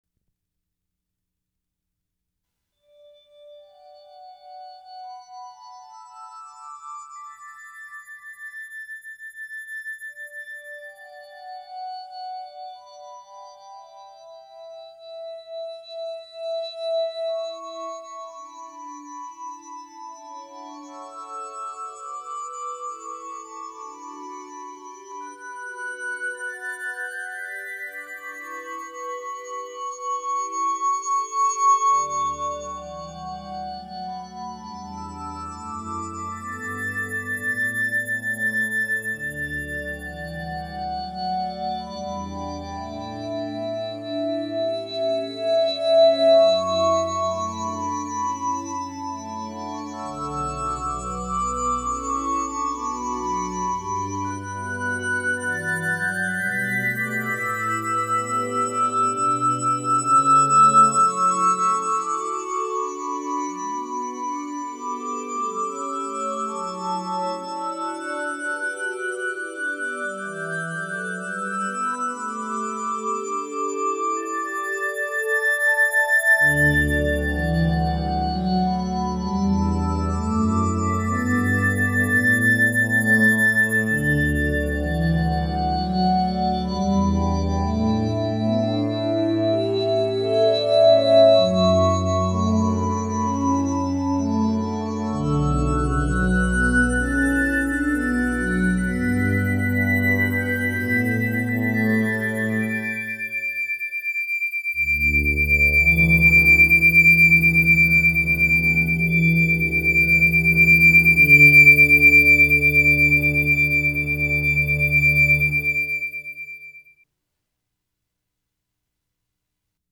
The performance was staged in a Sci Fi setting, so this Fanfare reflects that, using glass harmonica patches.
I’d suggest that listeners use headphones or low volume, since the bass notes might tax regular built-in computer speakers.
Fanfare_for_The_Tempest.mp3